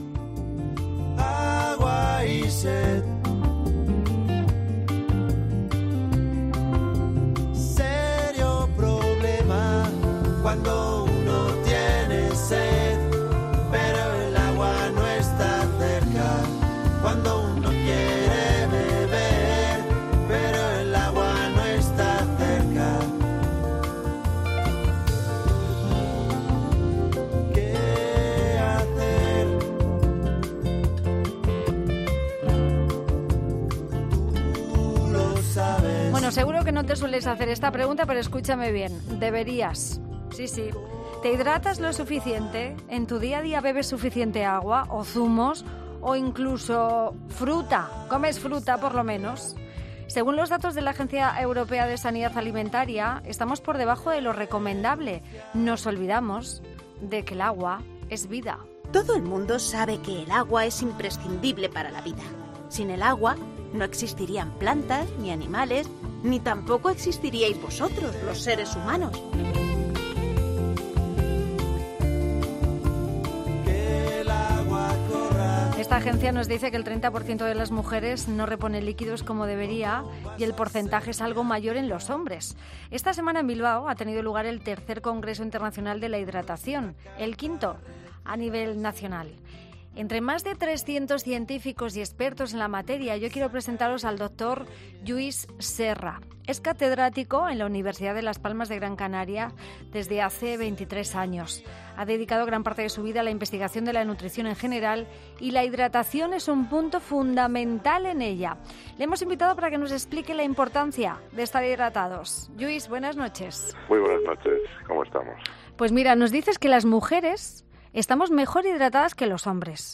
ESCUCHA LA ENTREVISTA COMPLETA SOBRE HIDRATACIÓN